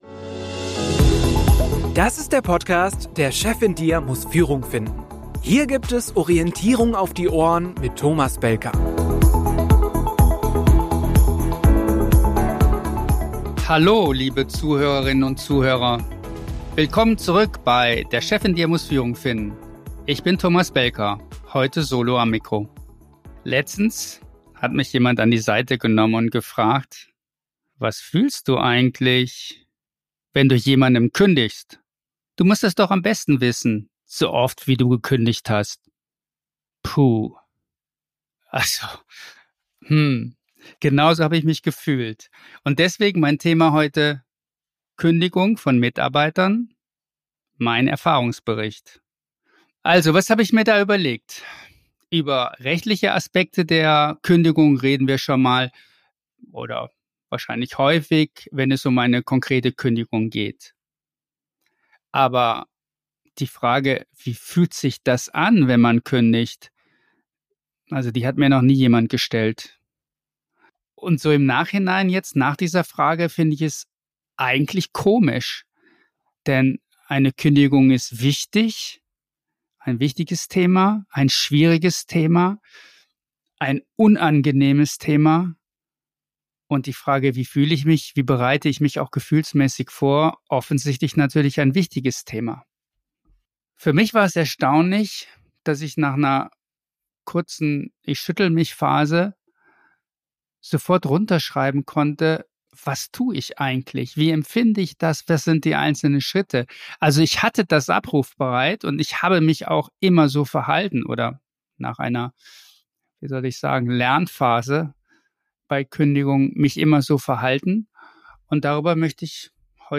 Solo-Folge